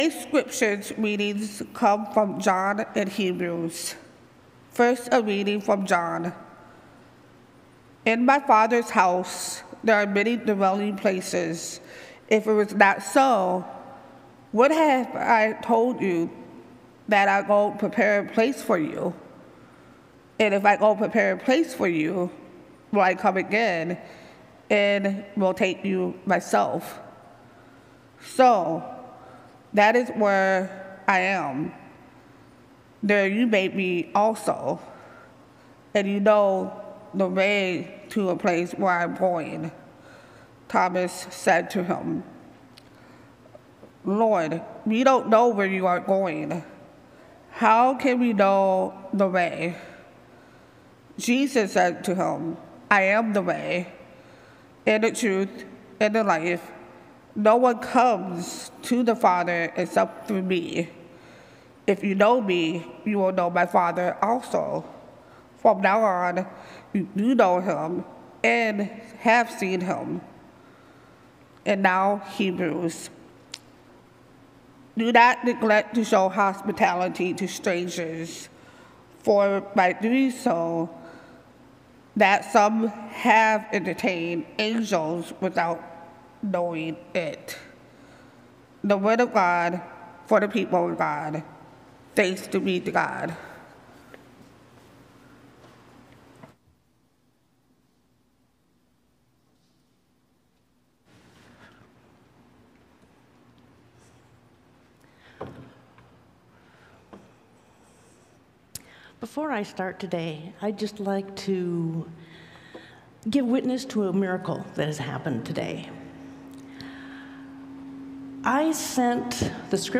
We’ll also hear a rousing spiritual sung by Bass